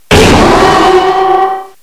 cry_not_mega_houndoom.aif